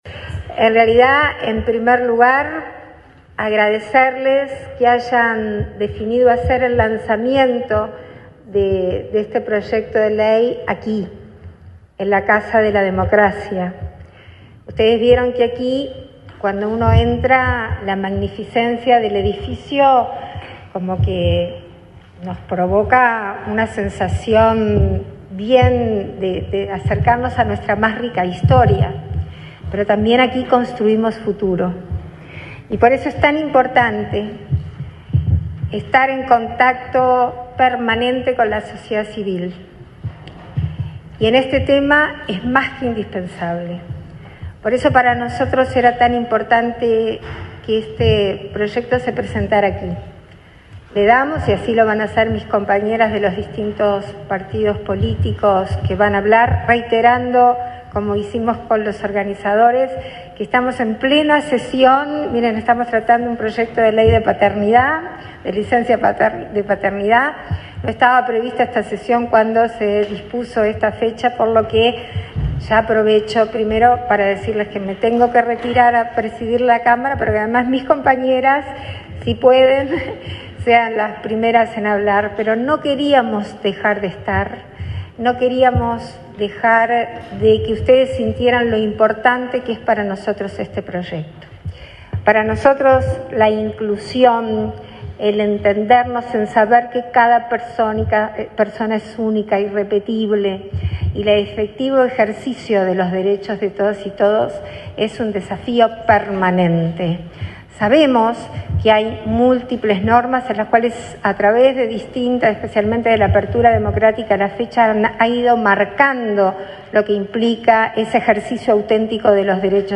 Palabras de autoridades en acto en el Palacio Legislativo
Palabras de autoridades en acto en el Palacio Legislativo 23/07/2024 Compartir Facebook X Copiar enlace WhatsApp LinkedIn La vicepresidenta de la República, Beatriz Argimón, y la directora de Discapacidad del Ministerio de Desarrollo Social (Mides), Karen Sass, participaron, este martes 23 en el Palacio Legislativo, en la presentación de un proyecto de ley para regular los apoyos y salvaguardias a fin de reconocer la capacidad jurídica de las personas con discapacidad en igualdad de condiciones.